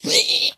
Minecraft Version Minecraft Version 1.21.5 Latest Release | Latest Snapshot 1.21.5 / assets / minecraft / sounds / mob / zombified_piglin / zpighurt2.ogg Compare With Compare With Latest Release | Latest Snapshot
zpighurt2.ogg